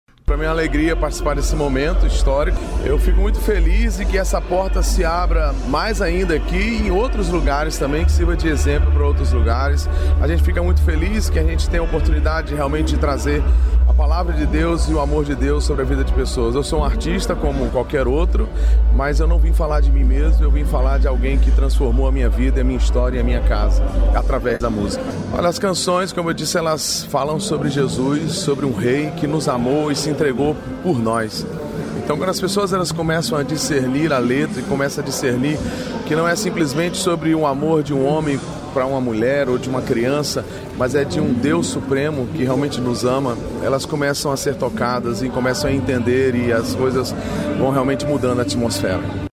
Sonora do cantor Fernadinho sobre a apresentação no Verão Maior Paraná